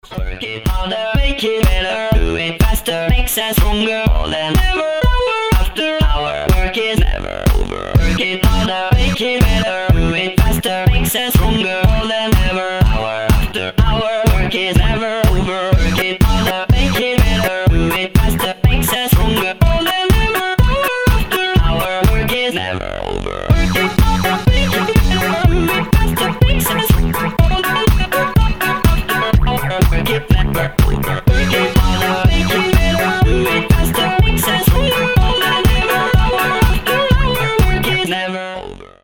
• Качество: 320, Stereo
ритмичные
громкие
мотивирующие
Electronic
house
электронный голос